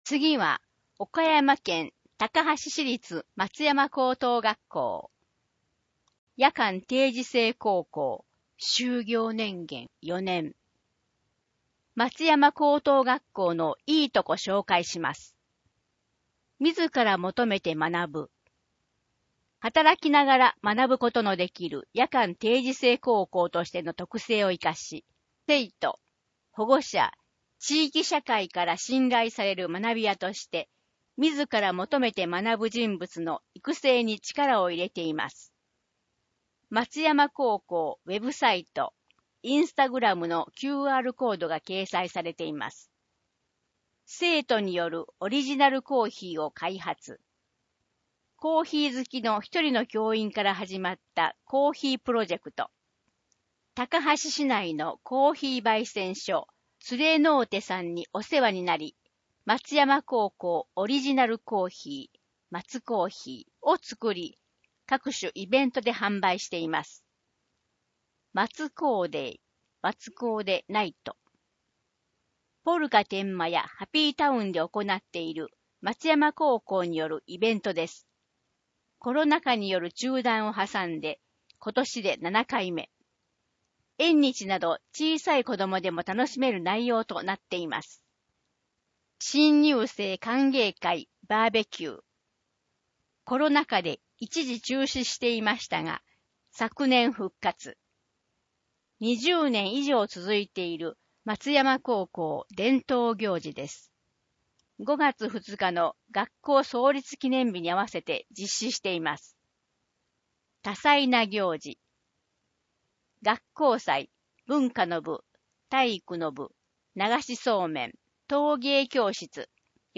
声の広報　広報たかはし10月号（240）